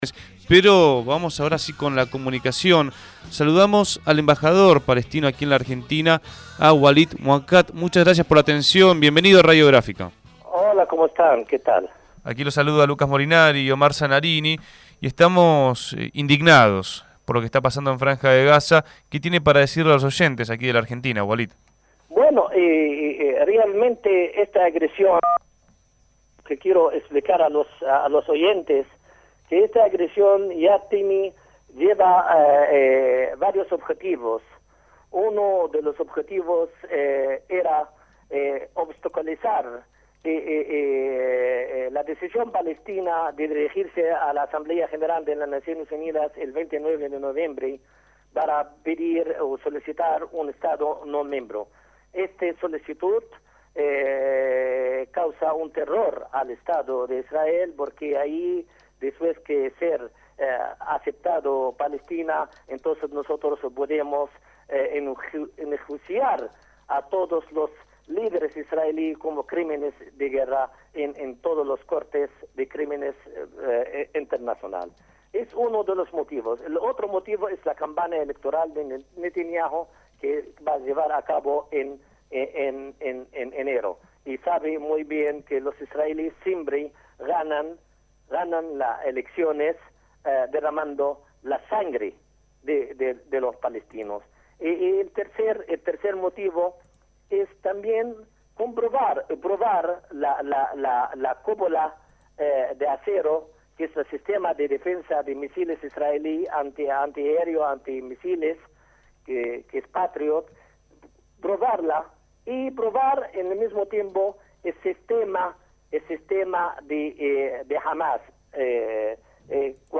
Walid Muaqqat, embajador del estado Palestino en Argentina, habló en Radio Gráfica sobre la dramática situación que se vive en la Franja de Gaza.